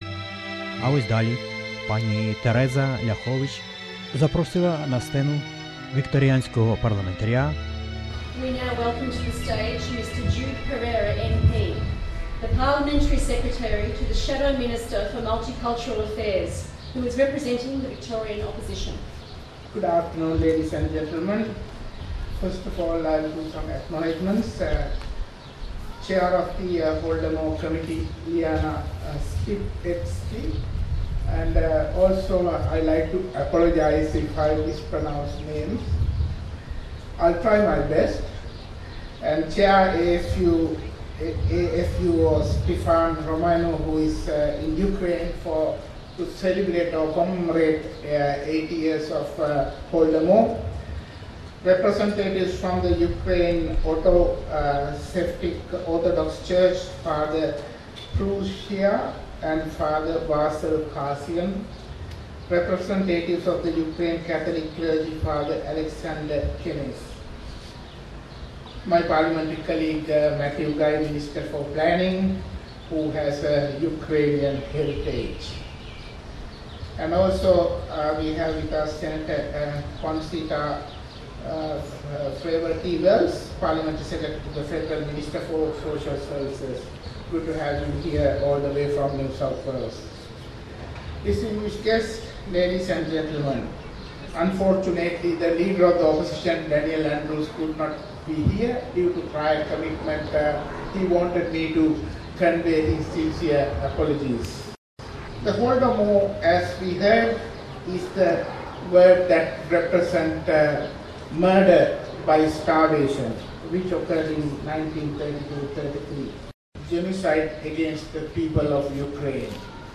Jude Perera, Parliamentary Secretary to the Shadow Minister for Multicultural Affairs, Victoria - speech, 23-11-23…